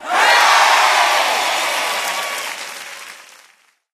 post_match_win_cheer_01.ogg